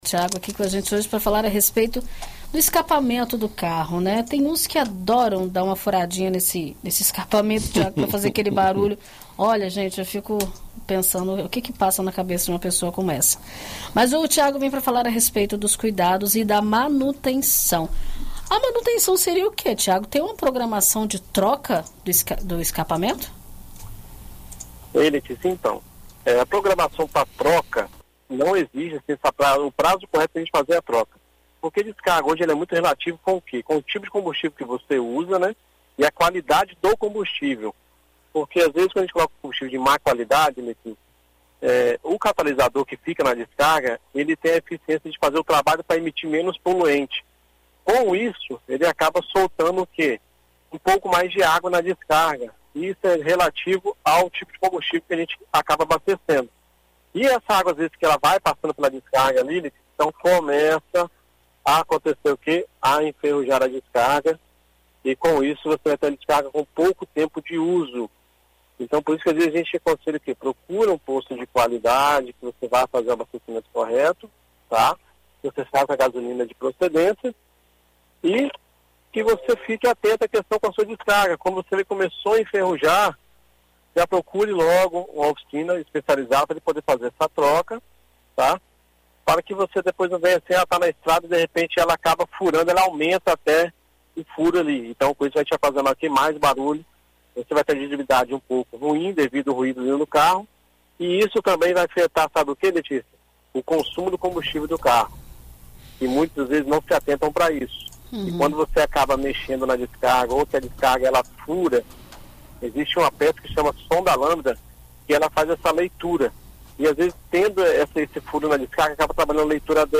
na BandNews FM Espírito Santo